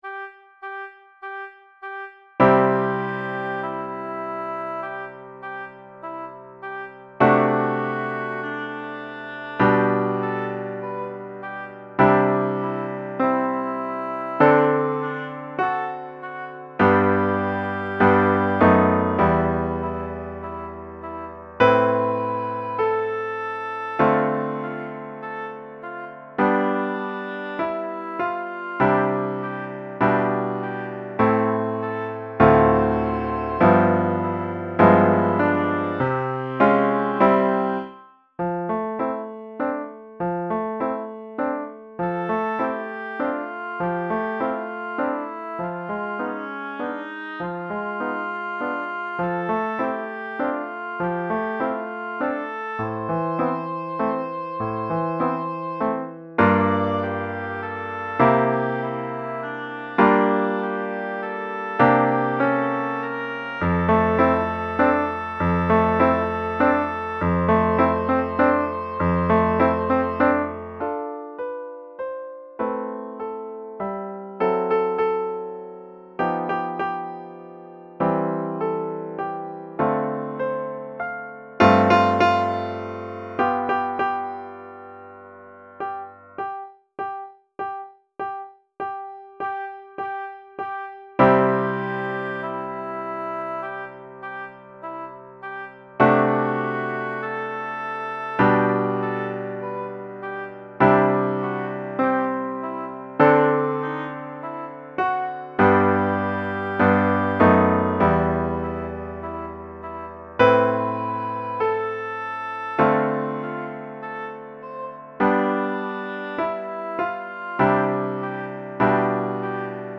Pour hautbois et piano DEGRE CYCLE 1